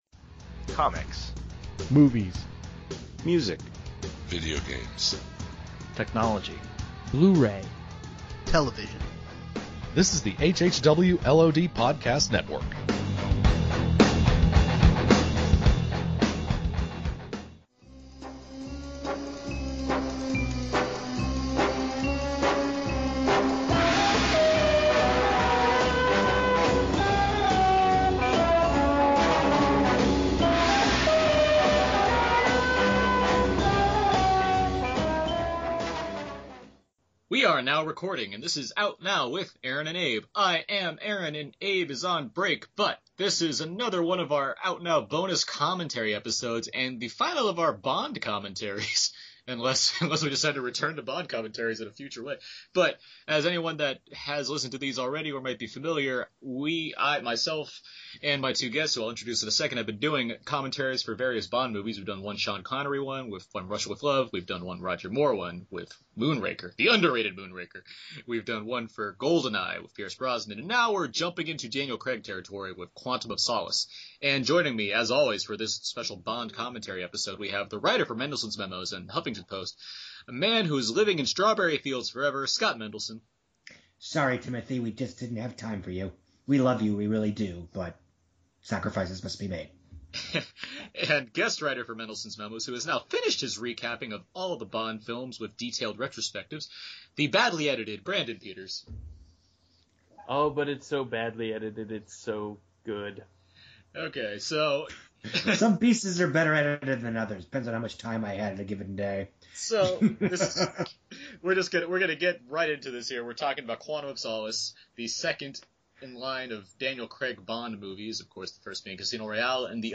Commentary, another Out Now Commentary.
A well timed commentary, as Skyfall is just days away from release, this track goes over the pros and cons of the second Daniel Craig entry, which was met with mixed reactions at the time. Fortunately, the participants are on both sides of this one and discuss the film in full, along with interjecting plenty of wild tangents along the way.